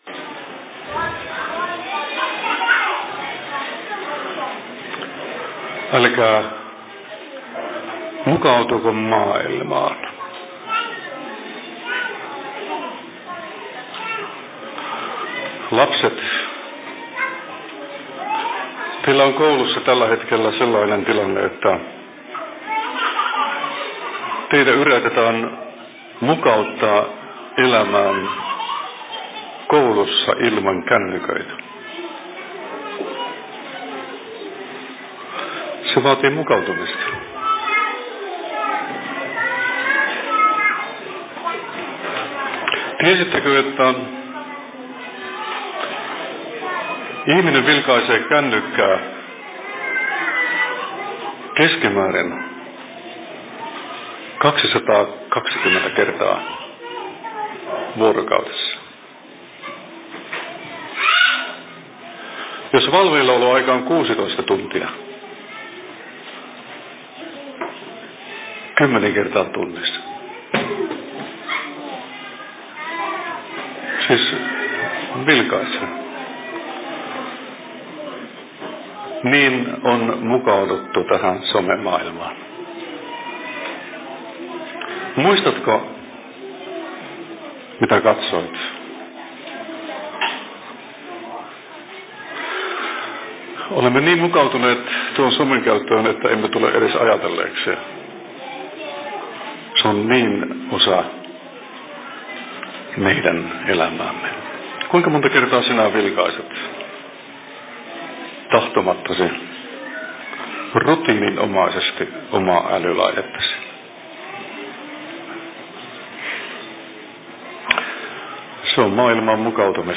Venetsialaiset/Puheenvuoro Tikkakosken RY:llä 30.08.2025 19.59
Paikka: Rauhanyhdistys Tikkakoski